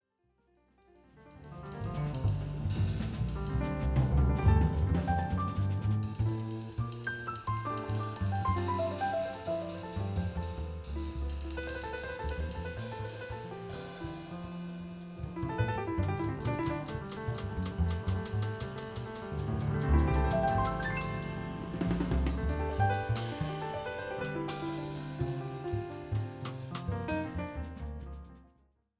Recorded principally at Van Gelder Studios
Piano, Keyboards Composer
Bass
Drums
Violin
Vocals